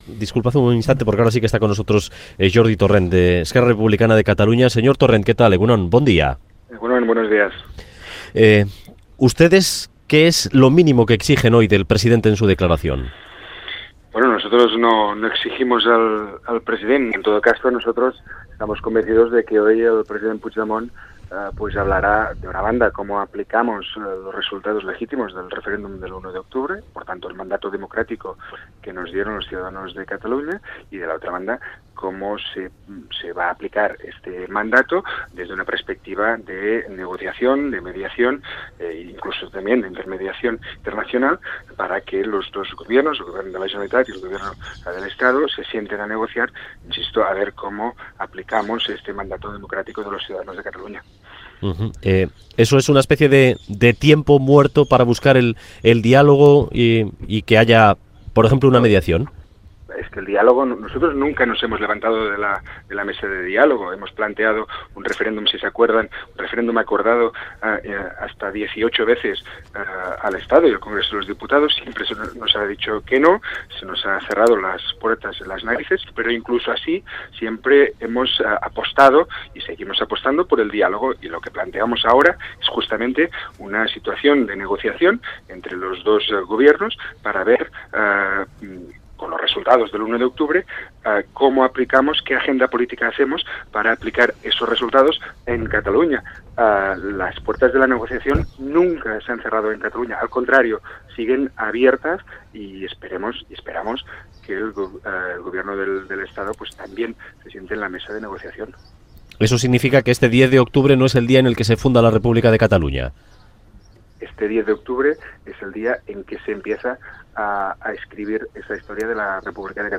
Audio: Roger Torrent, diputado de Ciutadans en el Parlament, entrevistado en el programa "Boulevard" de Radio Euskadi.